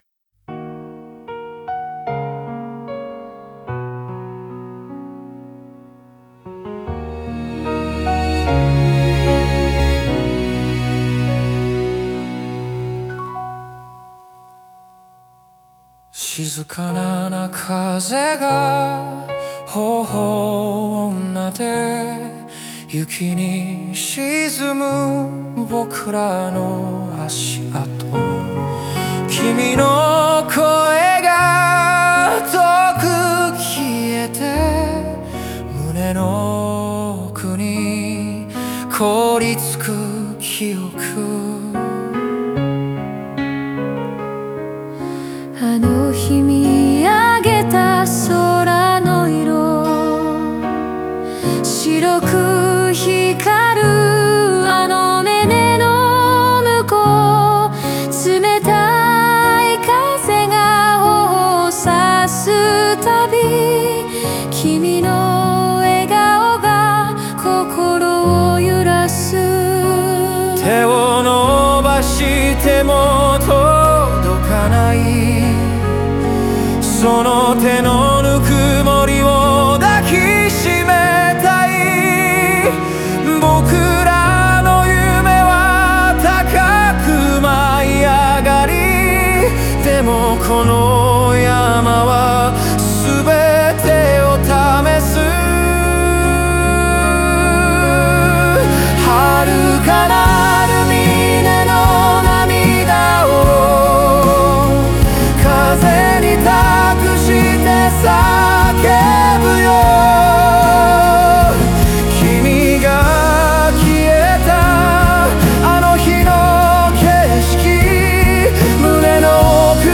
感情を爆発させ、失った仲間への愛や絆を胸に刻む決意を強く歌い上げます。